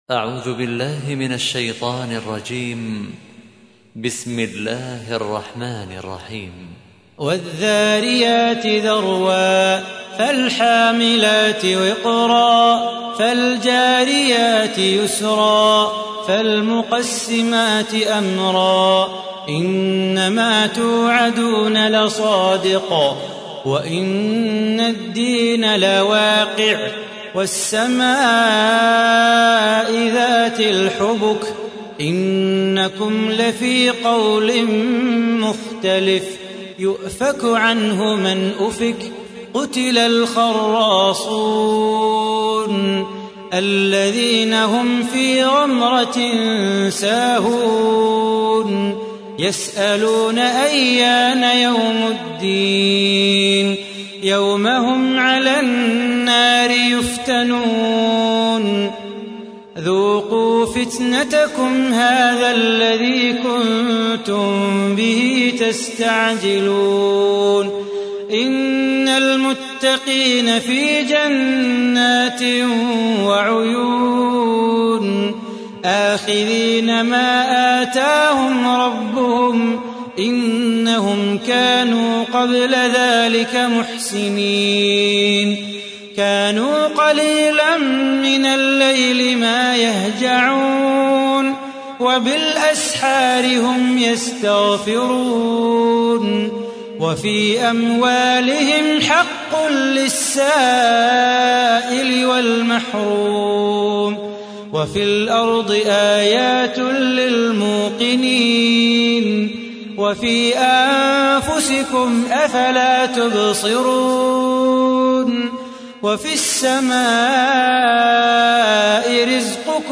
تحميل : 51. سورة الذاريات / القارئ صلاح بو خاطر / القرآن الكريم / موقع يا حسين